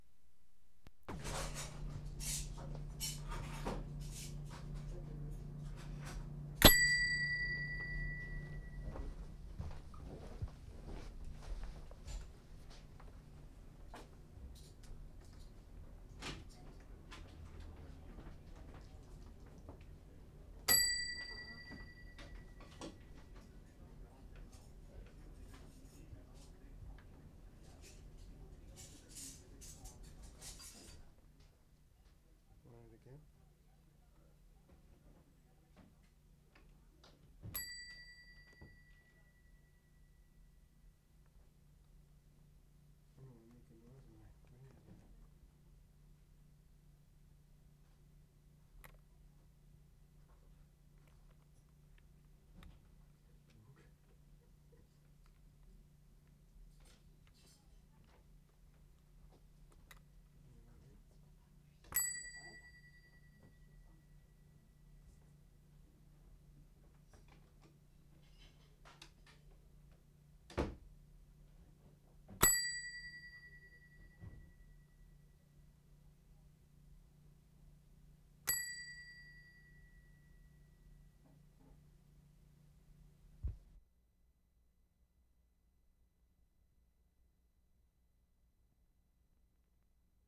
PERCÉ, QUEBEC Oct. 25, 1973
DESK BELL IN MOTEL 1'22"
7. Change of levels after second ring.